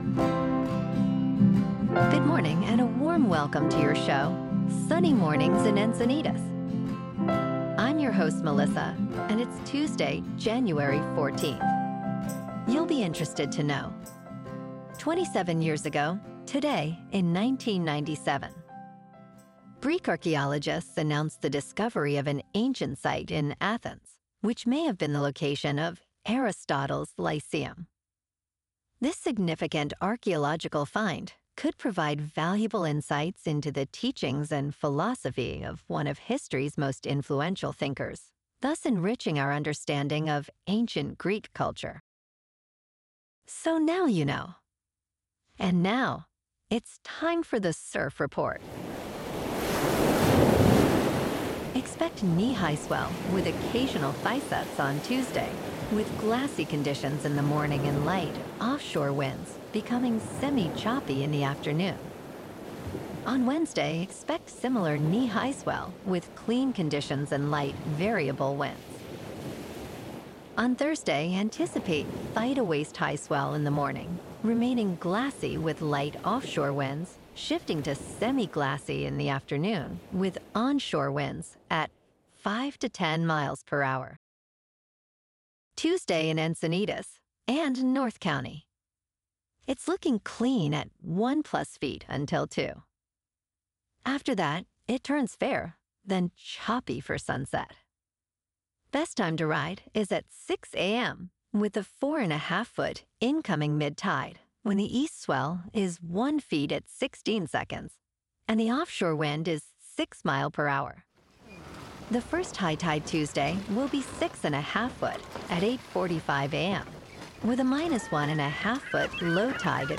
Your "Hyper-Local" 12 Minute Daily Newscast with: